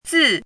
chinese-voice - 汉字语音库
zi4.mp3